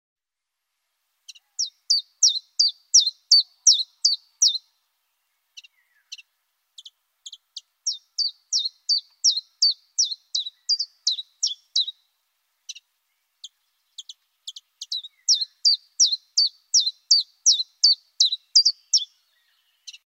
Chant 1
pouillot_veloce.mp3